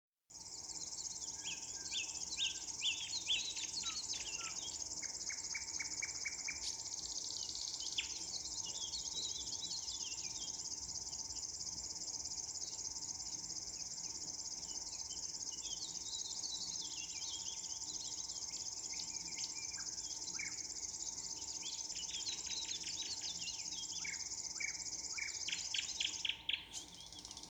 River Warbler, Locustella fluviatilis
StatusSinging male in breeding season